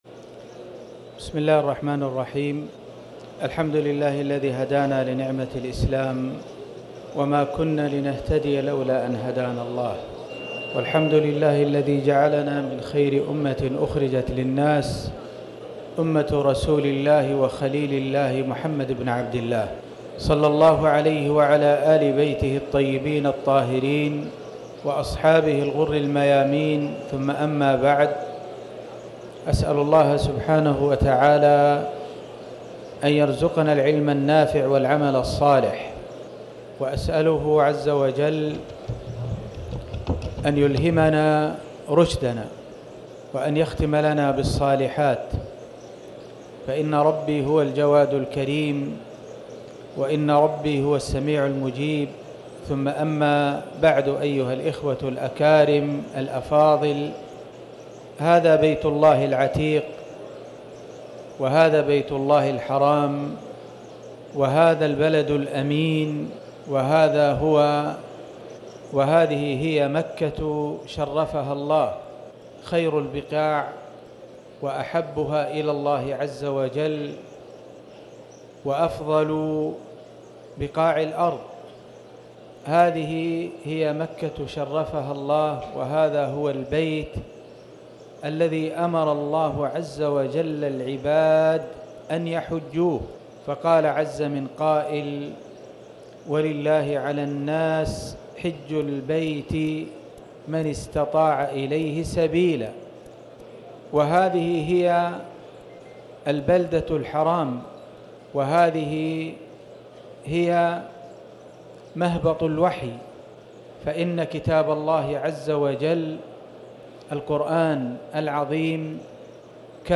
محاضرة أبرز معالم تجريد التوحيد وفيها: عظمة القرآن، ودلالة هذا من الوحي، وأول مقصود من مقاصد النبوة
المكان: المسجد الحرام
5ذو-الحجة-محاضرة-أبرز-معالم-تجريد-التوحيد11.mp3